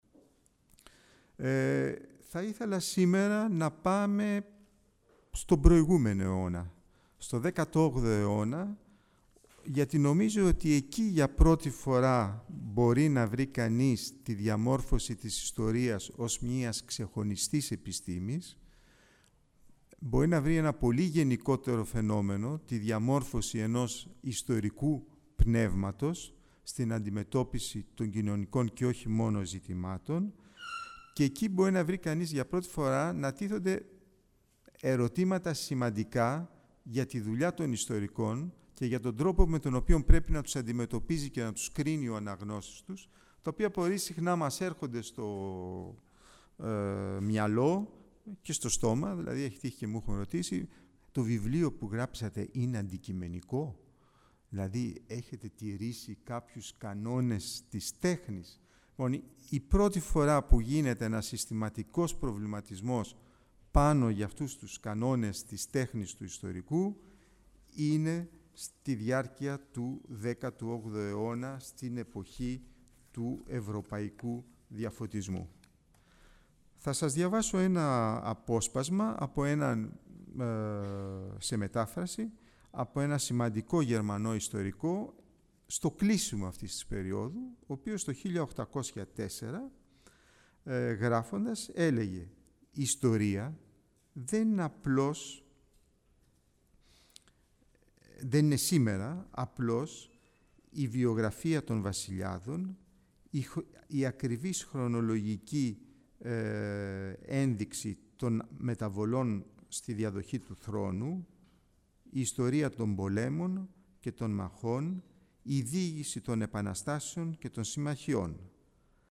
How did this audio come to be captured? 16 June 2004 at the chamber Theotokopoulos two lectures with the subject:"The origins of the Science of History" .